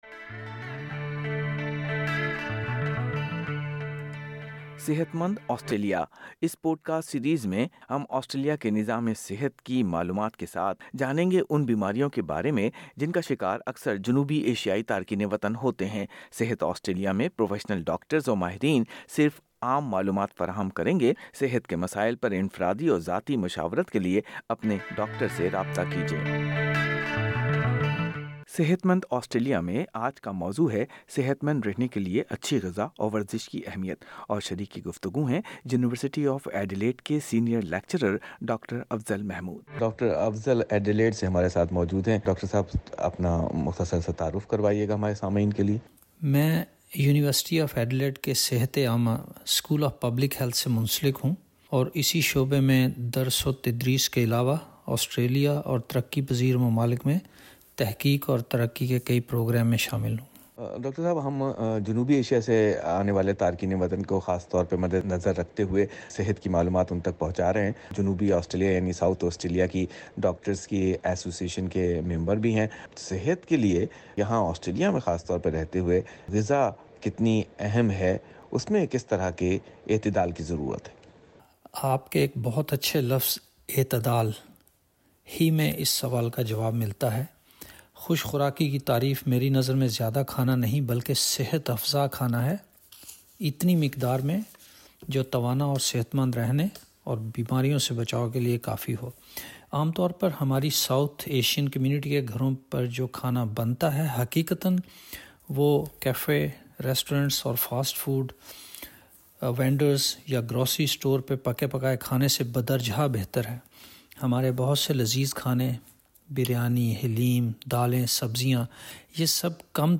بات چیت